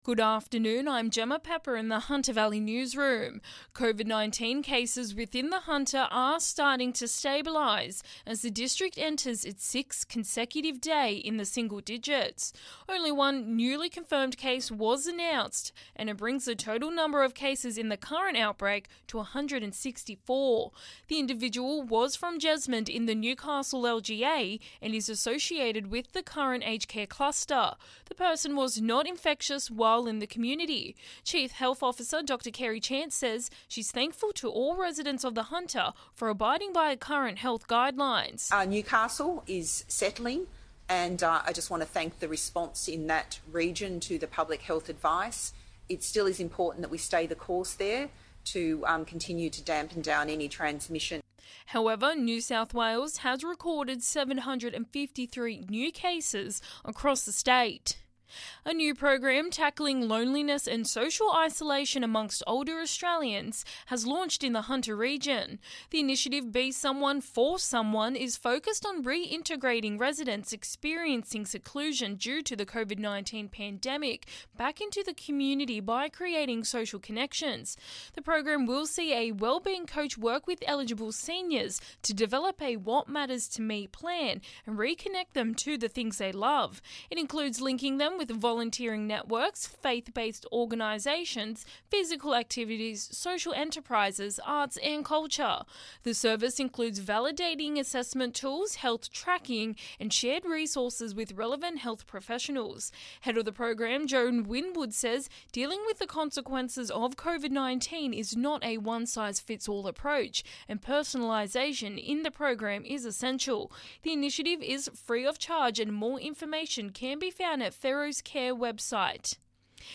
Listen: Hunter Local News Headlines 24/08/2021